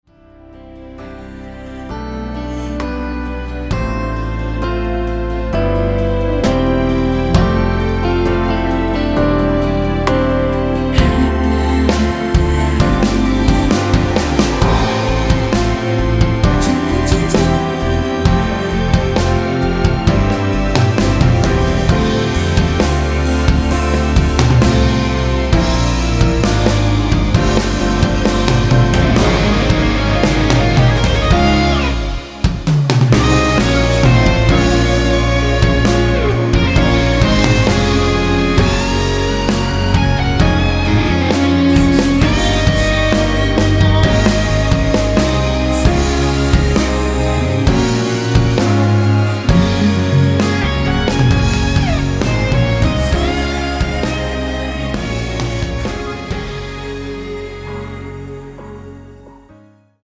원키에서(-1)내린 (1절앞+후렴)으로 진행되는 코러스 포함된 MR입니다.
앞부분30초, 뒷부분30초씩 편집해서 올려 드리고 있습니다.